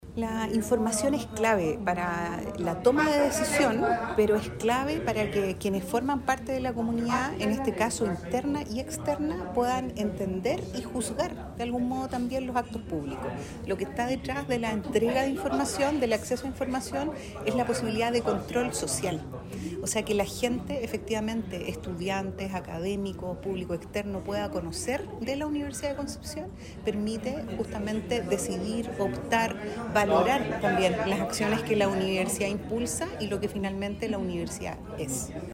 Con una fuerte apuesta por el fortalecimiento de la confianza pública y el acceso abierto a la información, las universidades agrupadas en la Red G9 celebraron el pasado viernes 28 de marzo el seminario “Transparencia en Educación Superior: avances y desafíos” en la Unidad de Santiago de la Universidad de Concepción (UdeC).